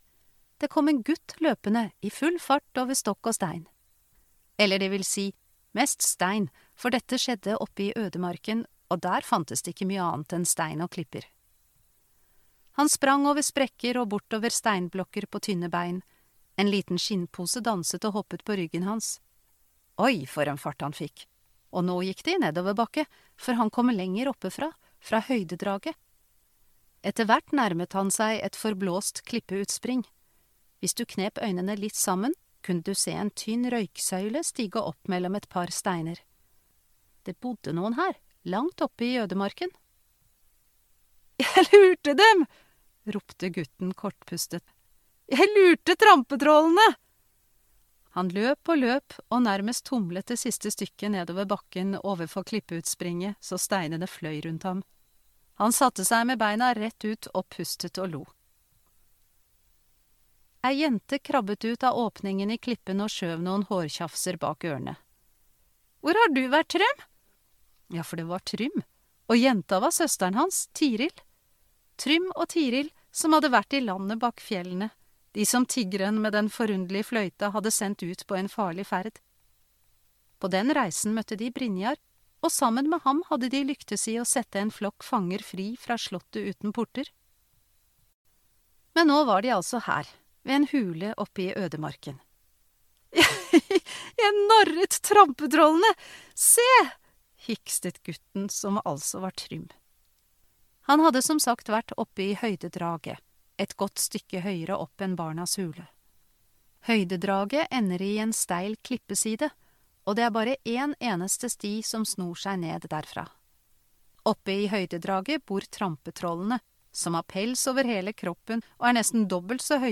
lydbok